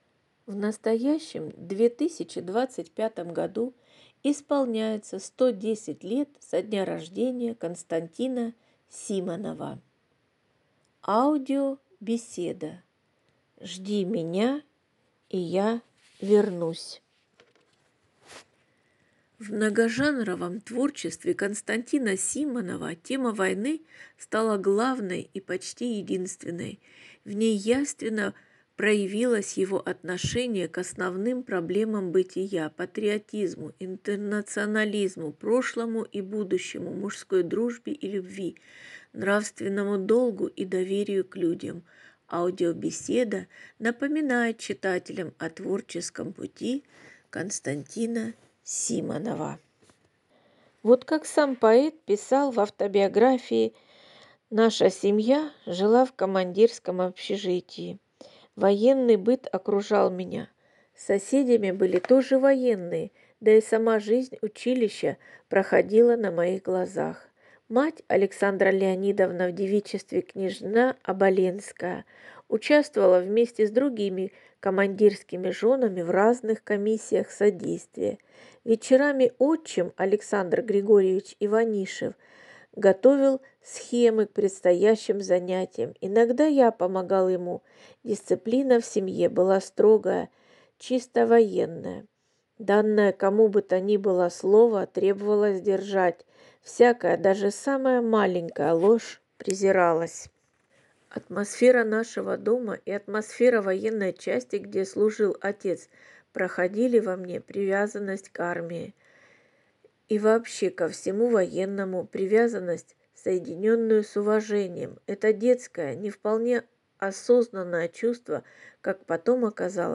Аудиобеседа «